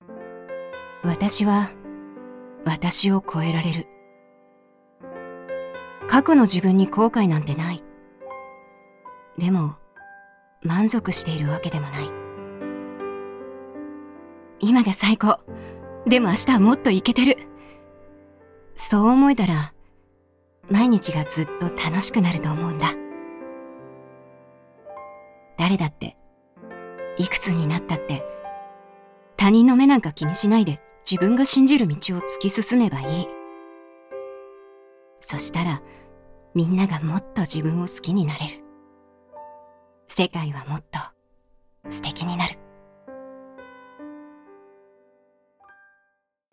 ボイスサンプル
ナレーション